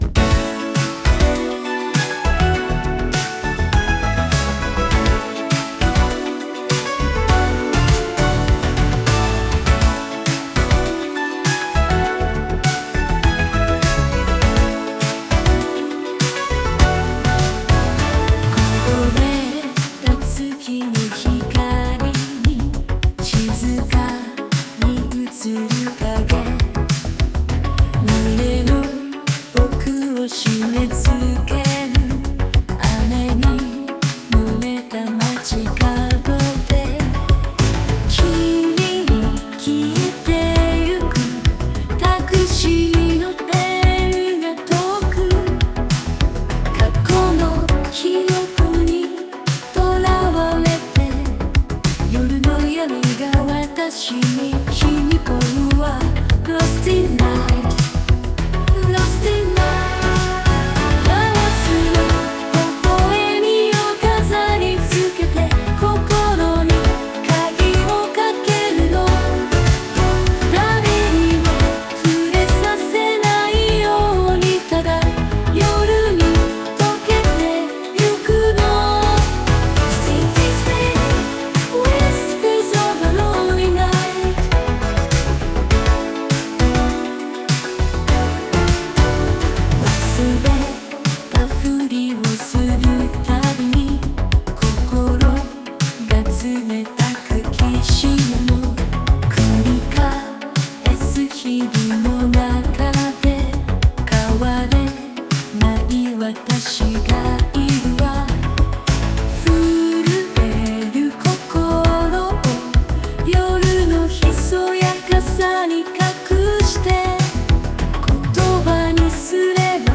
AI生成 懐メロ音楽集